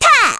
Ophelia-Vox_Attack2_kr.wav